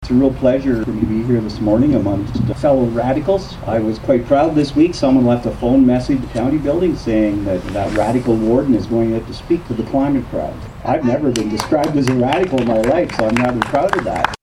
After about an hour of placard-waving surrounding the junction of Highways 60 and 41, the group retired to the serenity of the amphitheatre in Gerald Tracey Park to hear from a variety of speakers including one pragmatic Upper Tier radical.